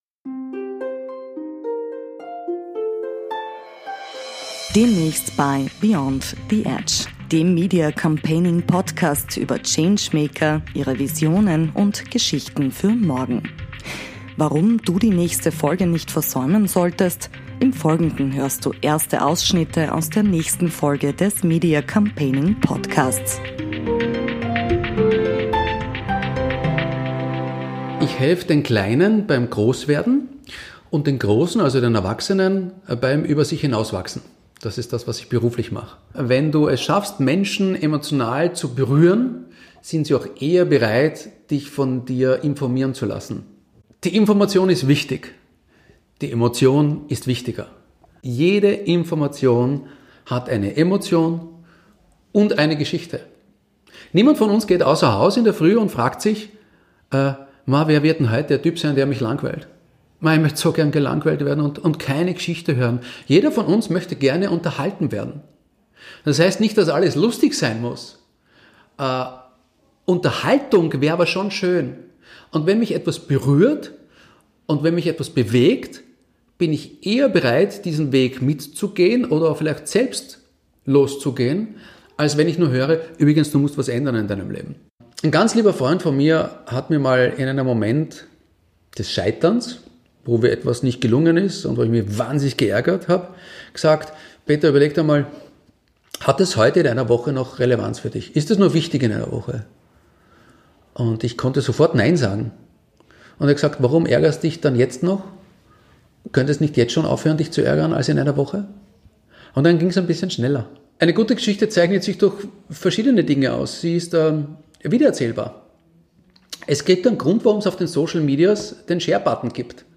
Wer andere bewegen will, muss sie zuerst berühren. Erste Ausschnitte aus einem richtig gutem Gespräch
Ausschnitte aus dem Gespräch.